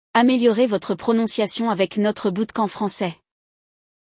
/Sefôrra/
/Michelãn/
/Carrfûr/
/Leroa Merlân/
/Chanélllll/
/Lûí Vitõn/
/Pêjô/
/Rênô/
/Cítroein/